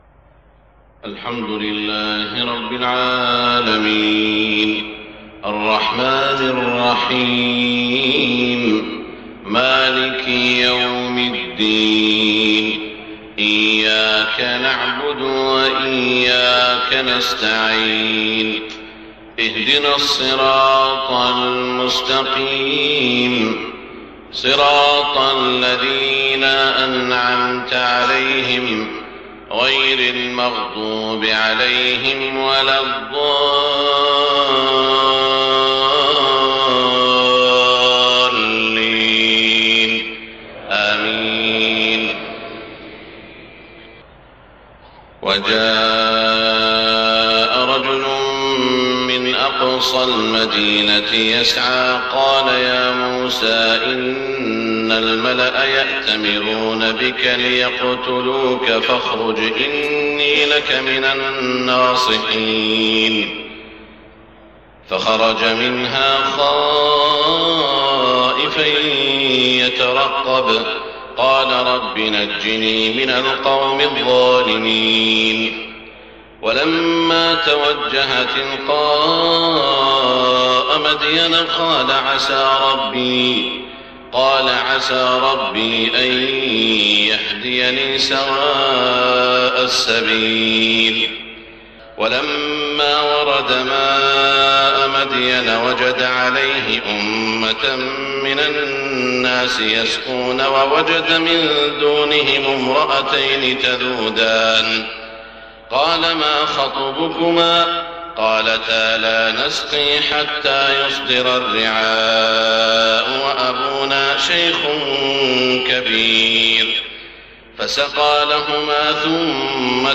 صلاة الفجر 1-6-1430هـ من سورة القصص > 1430 🕋 > الفروض - تلاوات الحرمين